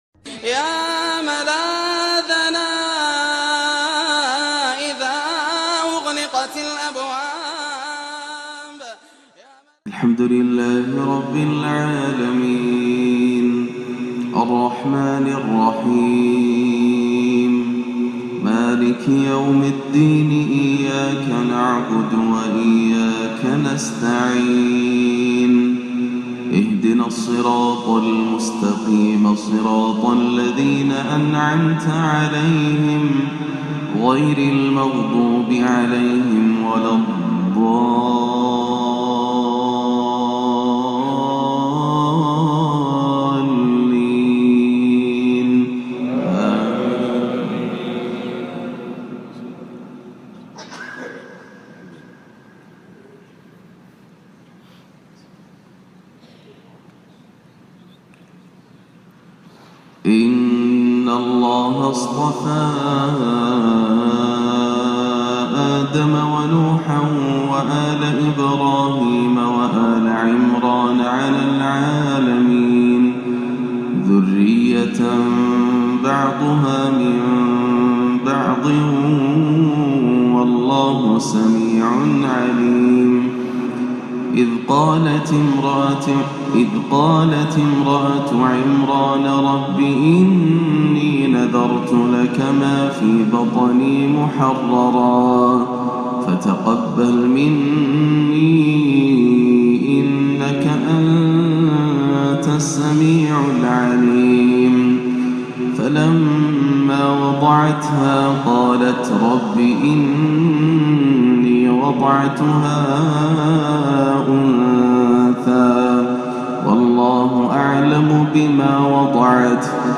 فجر الثلاثاء 1-2-1438هـ من سورة آل عمران 33-47 > عام 1438 > الفروض - تلاوات ياسر الدوسري